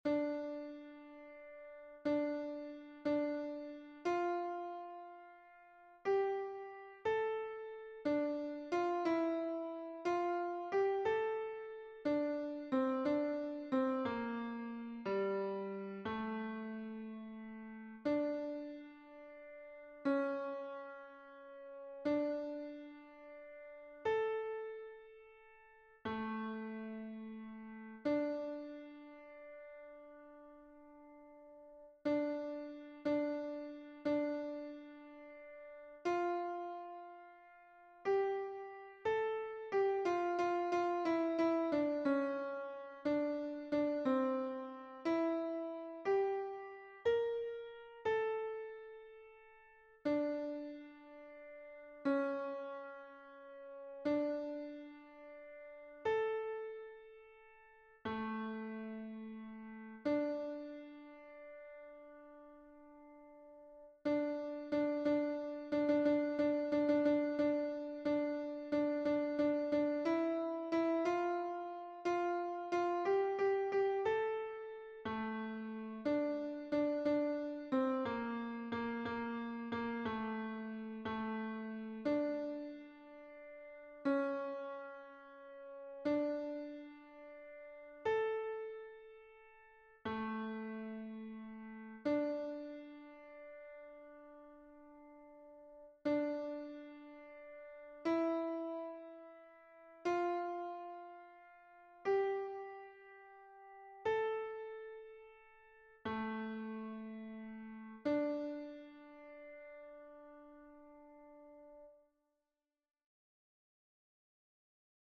Mp3 version piano
Hommes